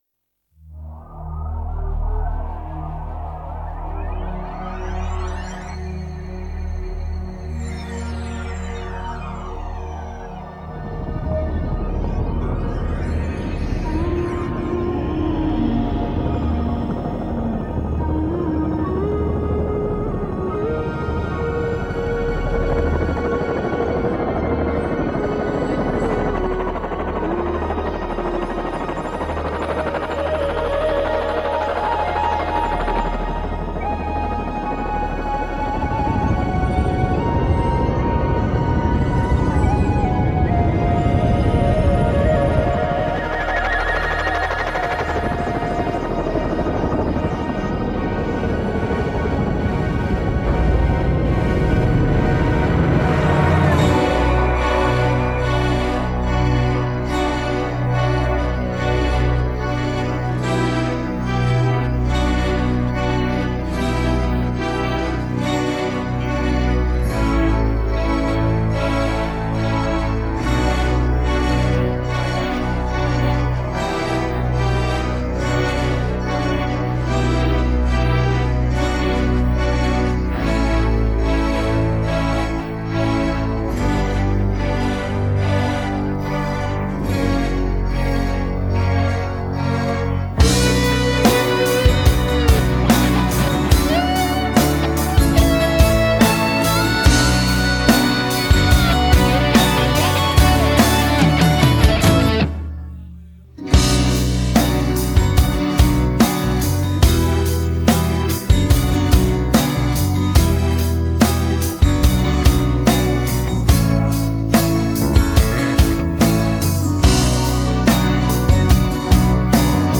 российская хеви-метал-группа
эпические гитарные риффы